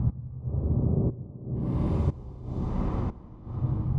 Index of /musicradar/sidechained-samples/120bpm